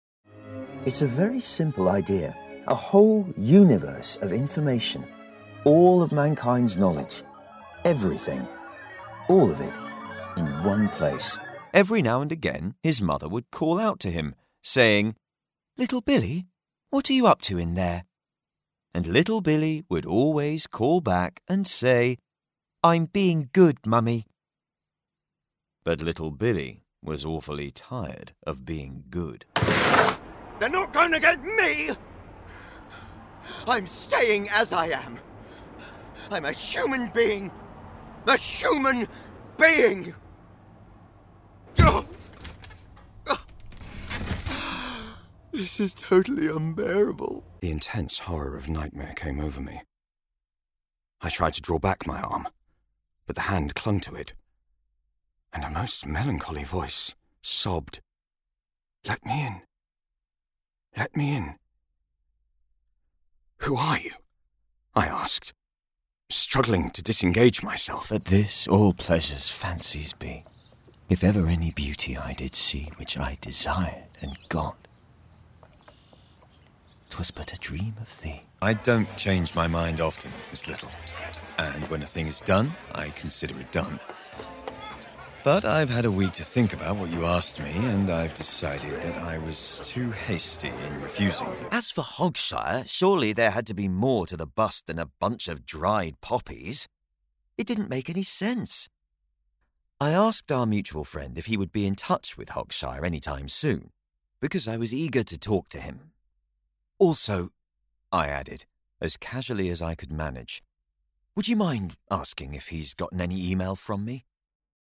AUDIOBOOK/AUDIODRAMA SHOWREEL
His deep, authentic RP voice lends youthful gravitas to commercials and narration, while his versatile accent range makes him a standout character actor for games, animation, and radio.
Male